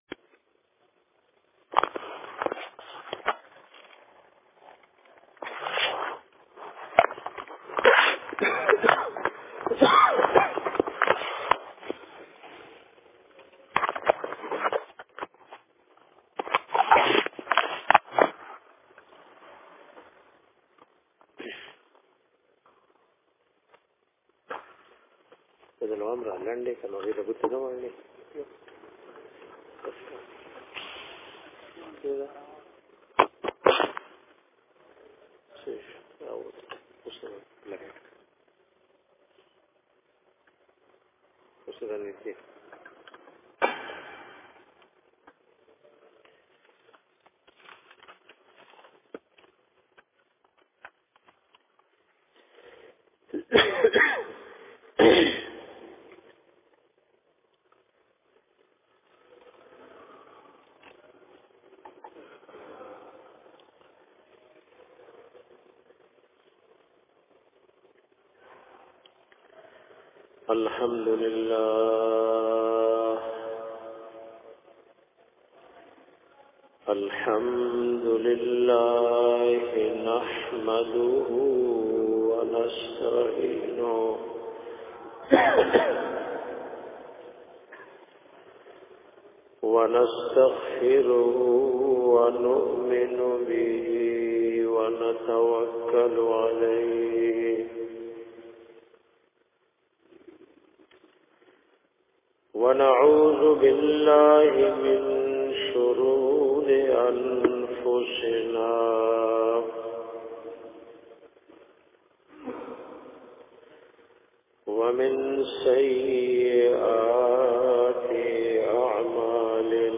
aaj ka jumma bayan